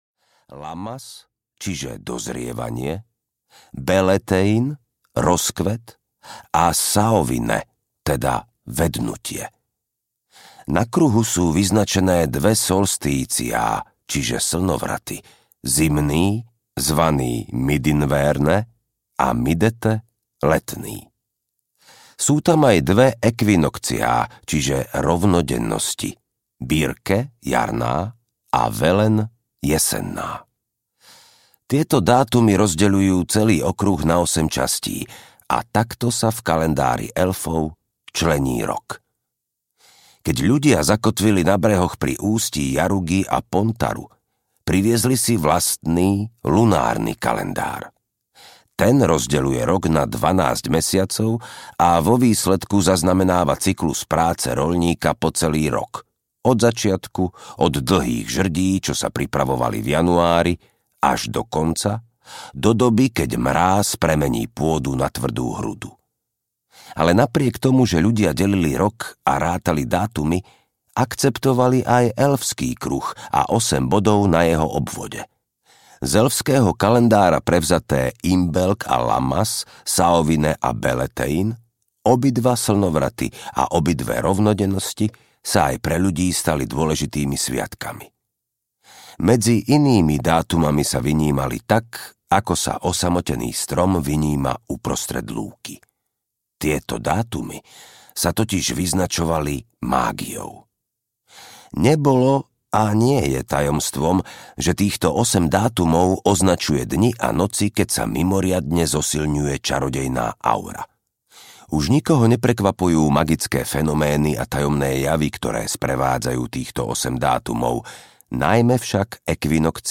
Zaklínač VI: Veža lastovičky audiokniha
Ukázka z knihy
zaklinac-vi-veza-lastovicky-audiokniha